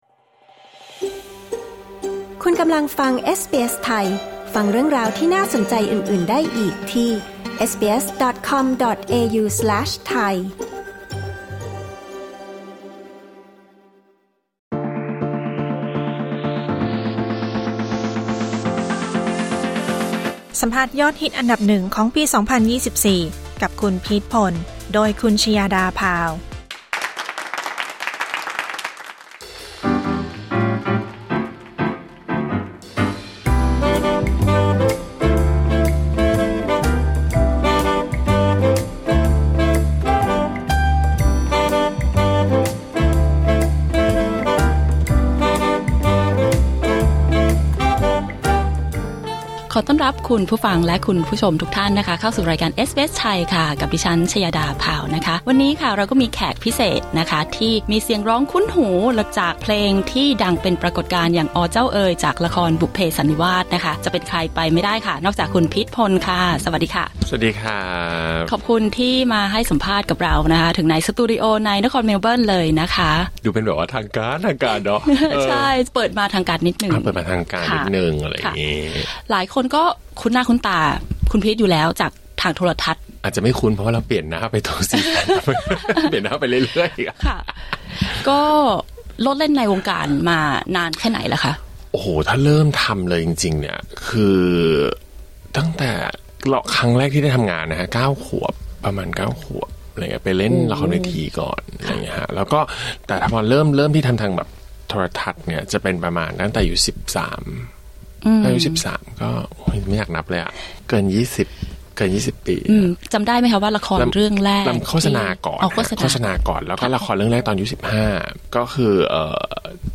บทสัมภาษณ์ยอดฮิตอันดับ 1 ของปี 2024 กับนักร้อง นักแสดงชื่อดัง "พีท พล" กับการค้นหา passion ครั้งใหม่ ในออสเตรเลีย